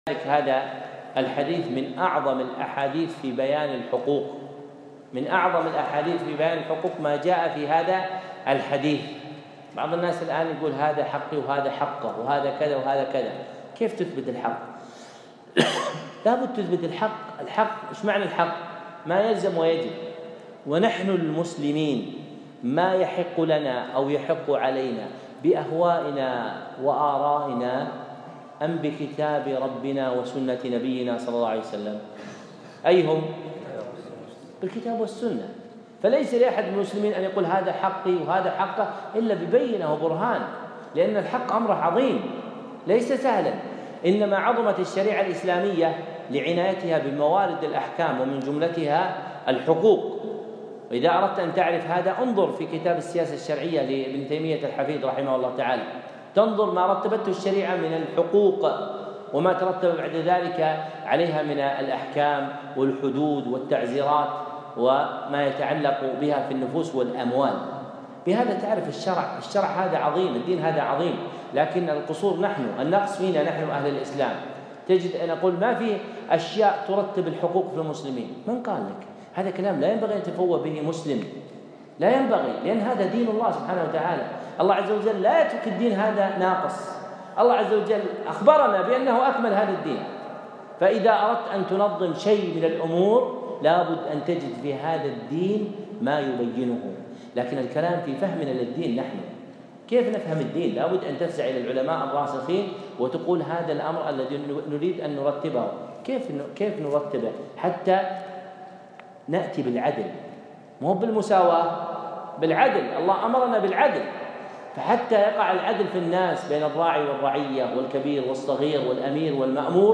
موعظة بليغة في الحقوق